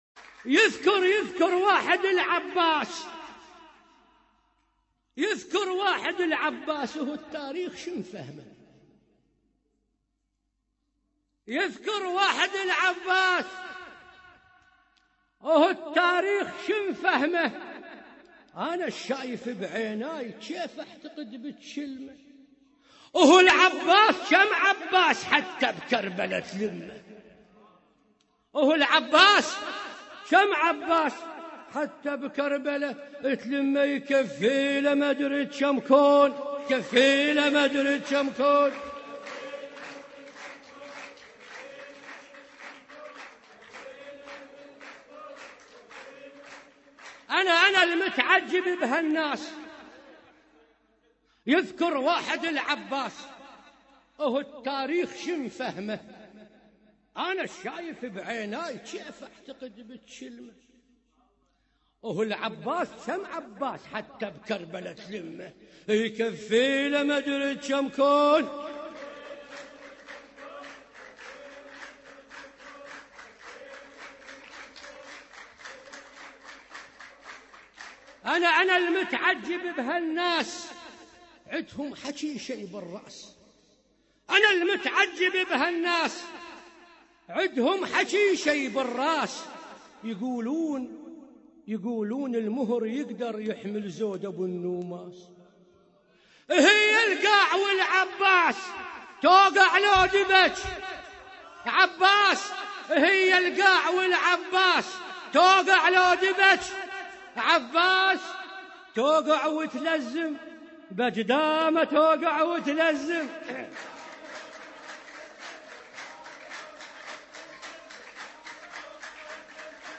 هـــــــوسات لابي الفضل العباس عليه السلام mp3